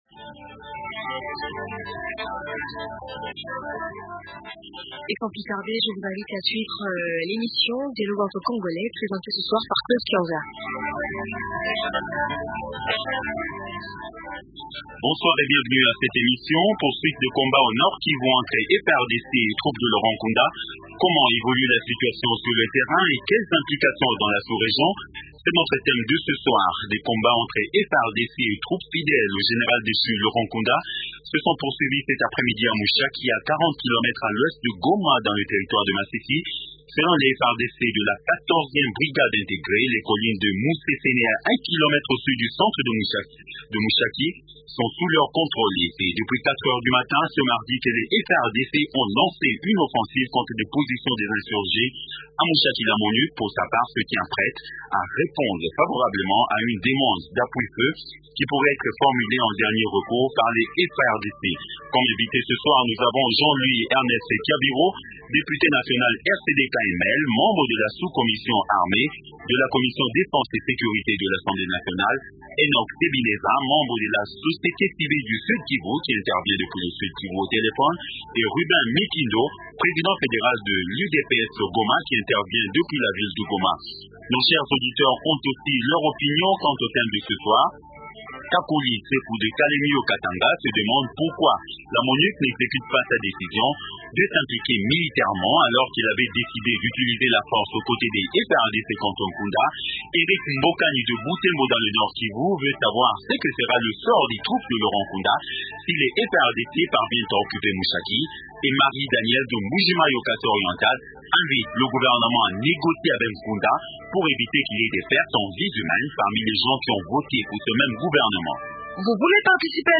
Invitésrn rn- Jean- Louis Ernest Kyaviro, député national RCD/KML, membre de la sous commission armée de la commission défense et sécurité de l’assemblée nationale. - Enock Sebineza, membre de la société civile du Sud Kivu et député national honoraire.